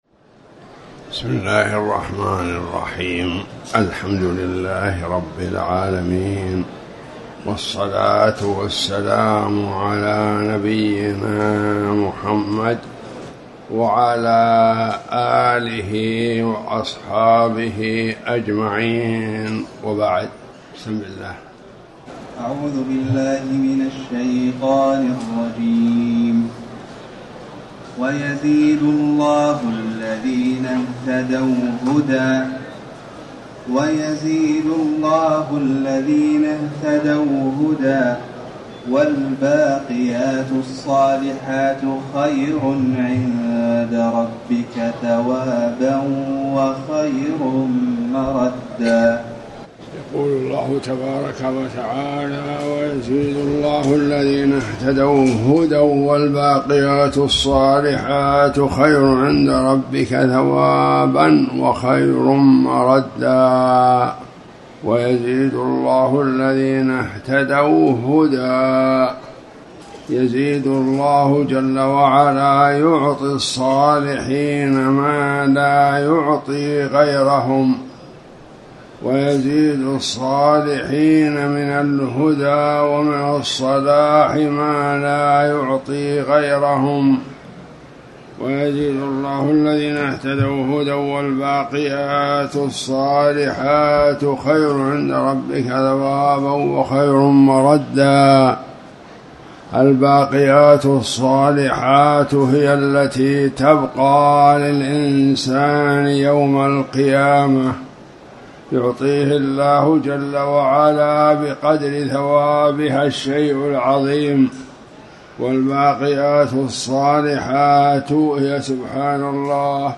تاريخ النشر ١٢ ذو القعدة ١٤٣٩ هـ المكان: المسجد الحرام الشيخ